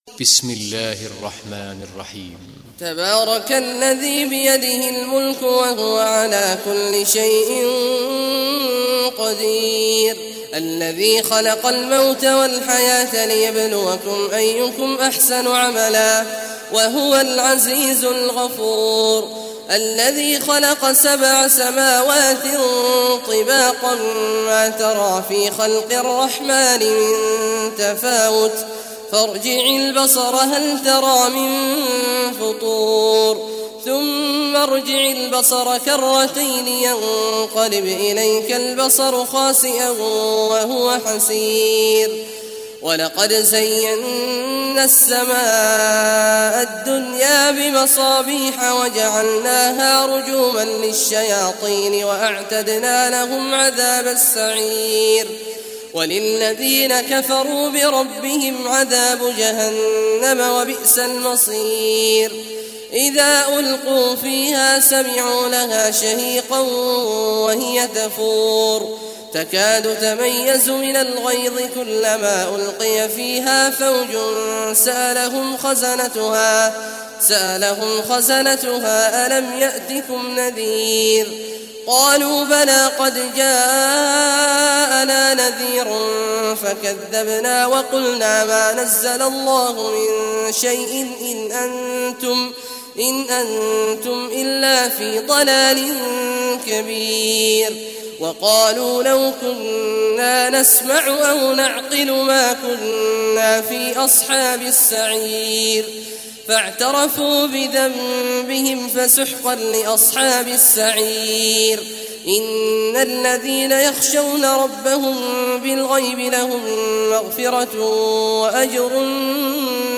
Surah Mulk Recitation by Sheikh Awad Juhany
Surah Mulk, listen or play online mp3 tilawat / recitation in Arabic in the beautiful voice of Sheikh Abdullah Awad al Juhany.